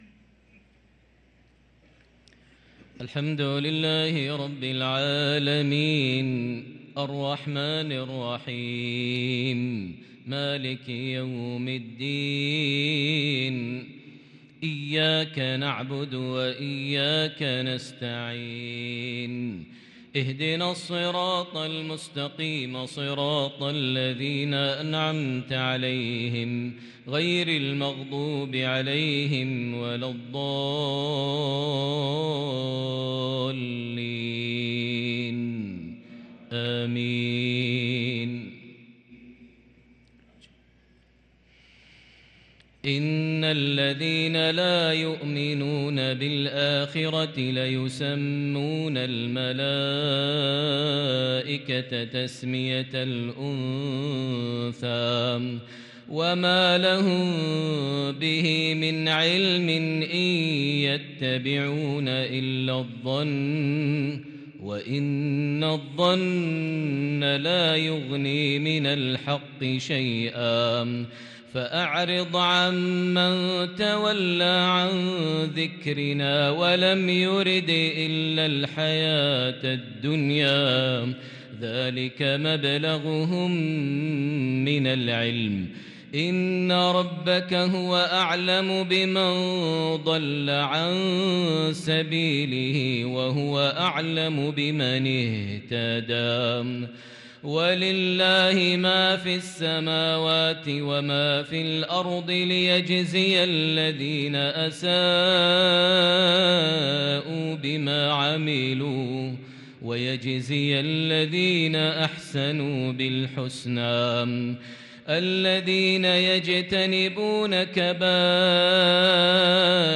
صلاة العشاء للقارئ ماهر المعيقلي 23 ربيع الأول 1444 هـ